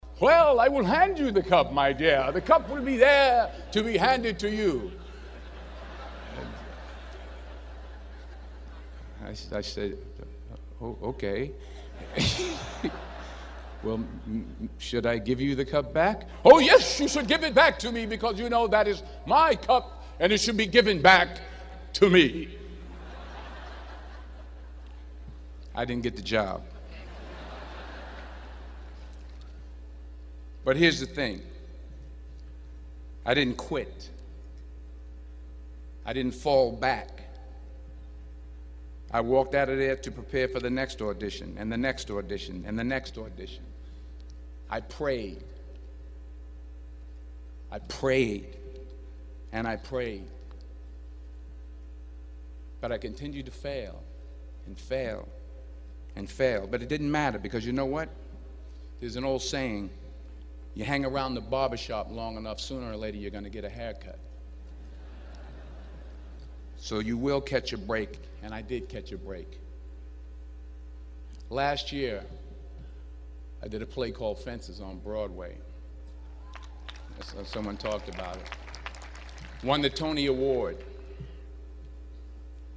公众人物毕业演讲第425期:丹泽尔2011宾夕法尼亚大学(9) 听力文件下载—在线英语听力室